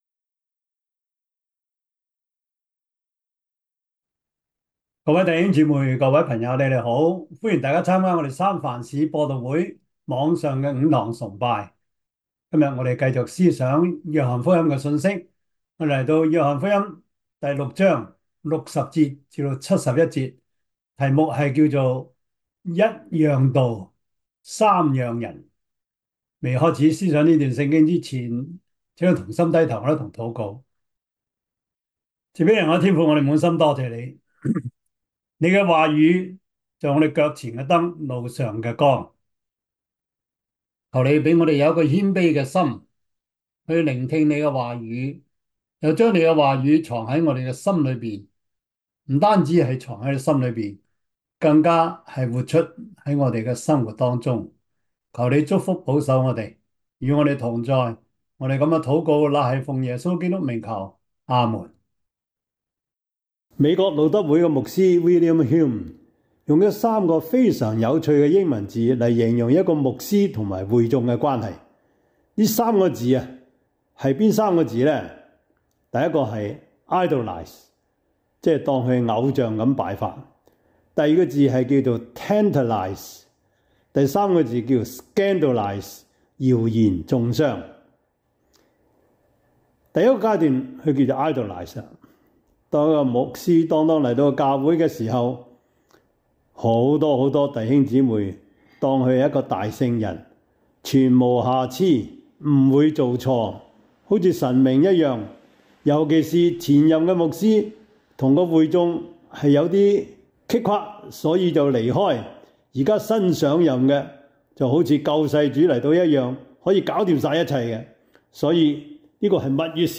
約翰福音 6:60-71 Service Type: 主日崇拜 約翰福音 6:60-71 Chinese Union Version
Topics: 主日證道 « 不吐不快 第四十課: 文化,神學,倫理 (2) »